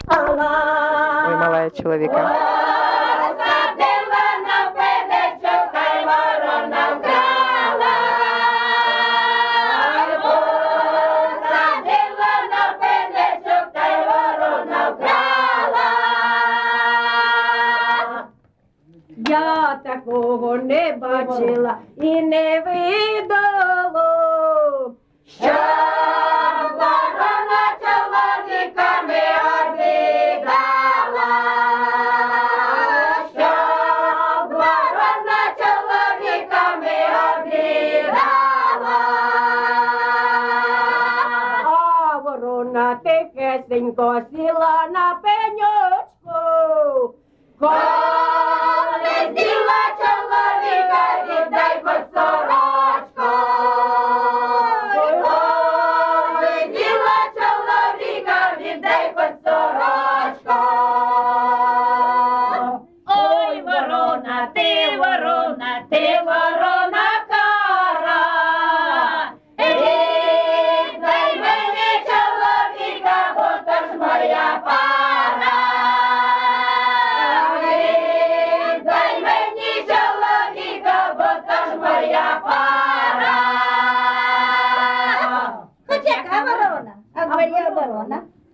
ЖанрЖартівливі
Місце записус. Шарівка, Валківський район, Харківська обл., Україна, Слобожанщина